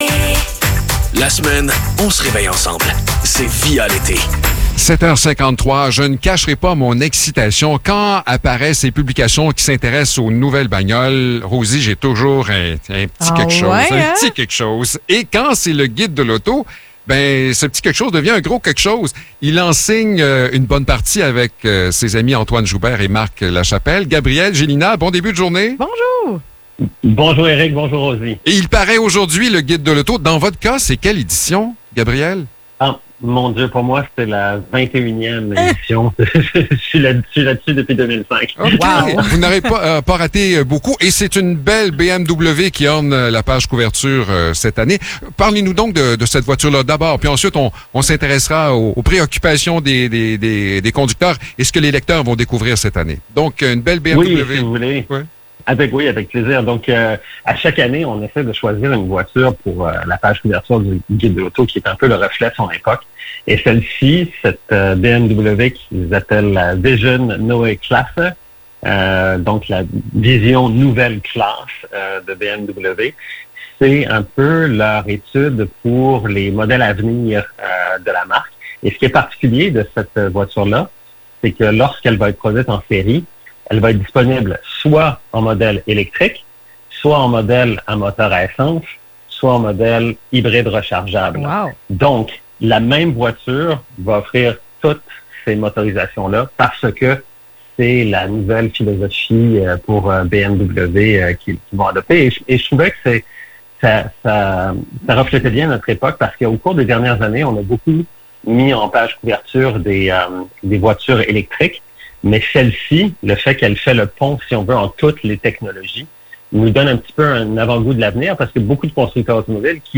Entrevue pour le guide de l’auto 2025